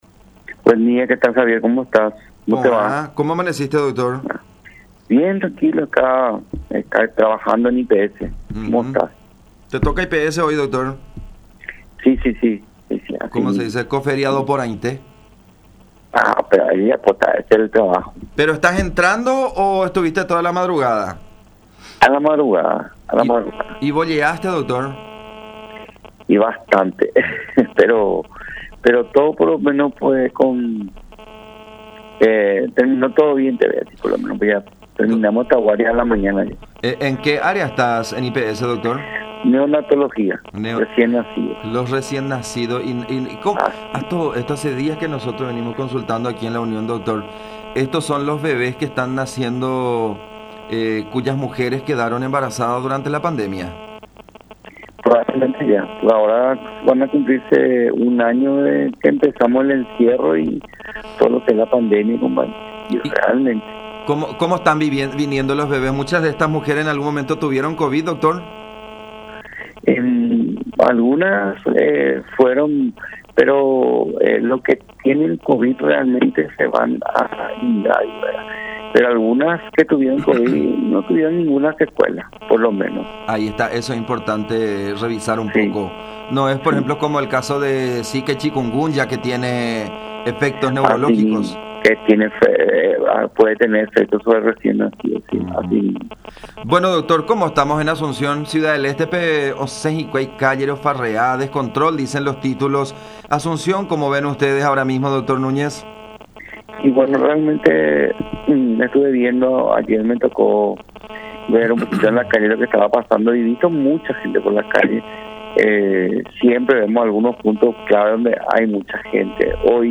“Ayer me tocó ver algunas situaciones en las calles de Asunción, en zona de la Costanera y había mucha aglomeración (…) Comprendemos que las personas se cansan, pero de igual manera tenemos que seguir con los cuidados, porque a la hora de pedir terapia se desesperan”, manifestó Núñez en conversación con La Unión.